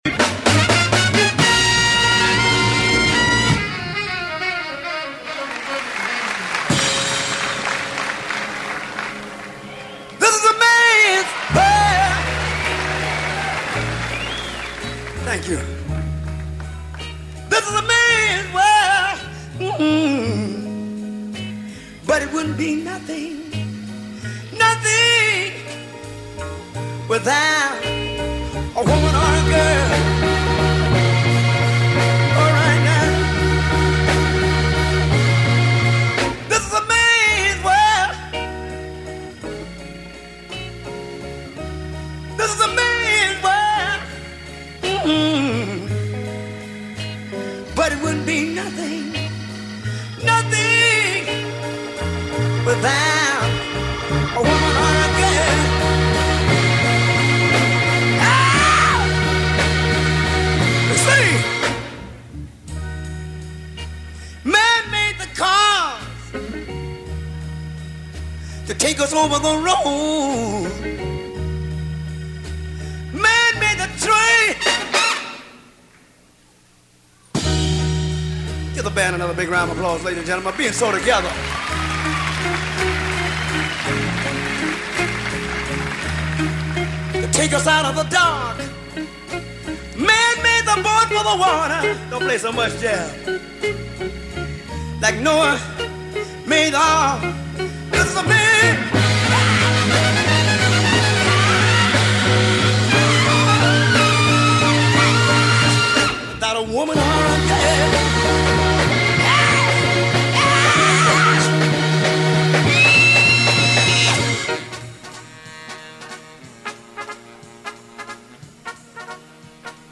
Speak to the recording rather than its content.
Augusta concert